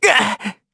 Evan-Vox_Damage_kr_05.wav